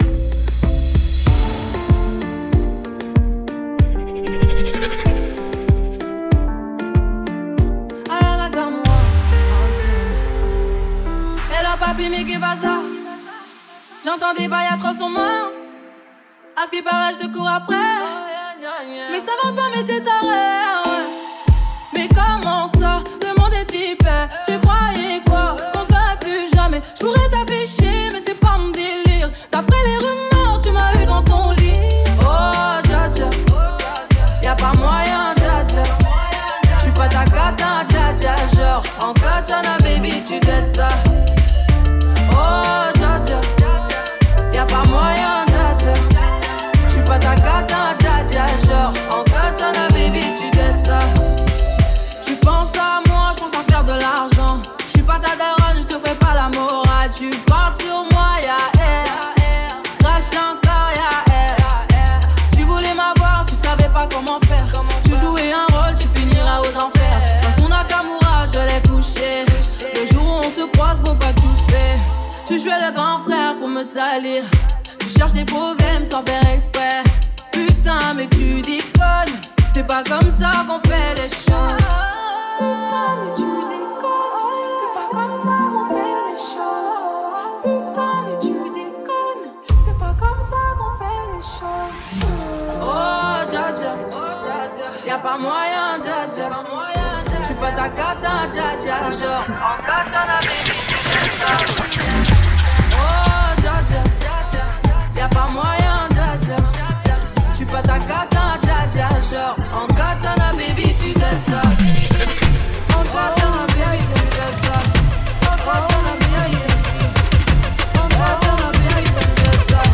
smooth bachata vibes